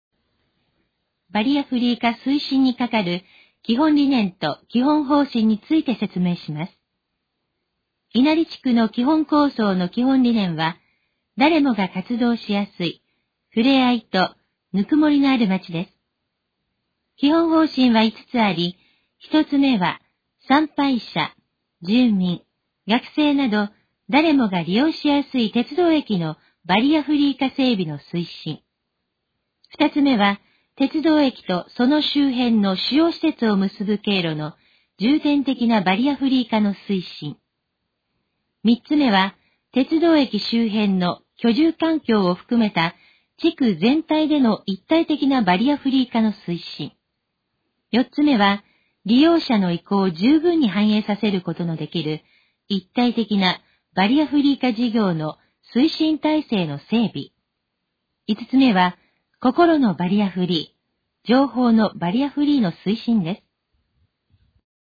このページの要約を音声で読み上げます。
ナレーション再生 約127KB